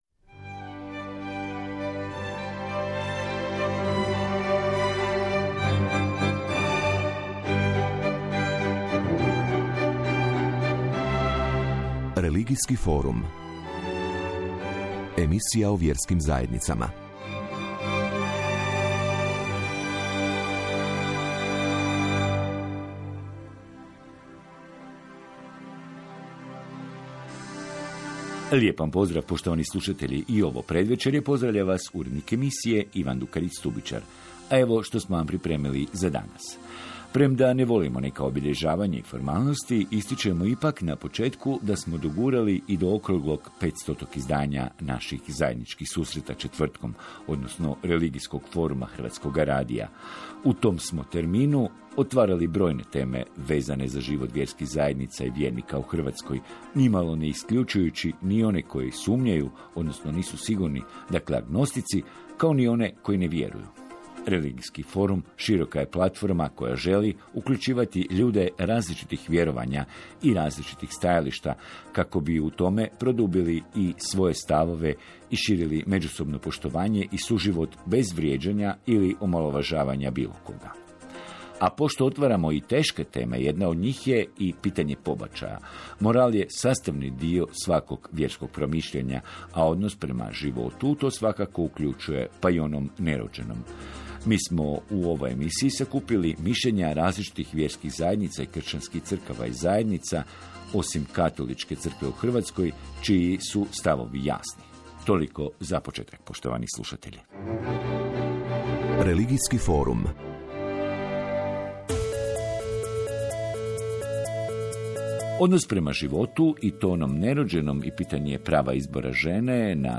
U emisiji Religijski forum Hrvatskog radija govorilo se o pobačaju. O ovoj temi govorili su predstavnici vjerskih zajednica, đakon Srpske pravoslave crkve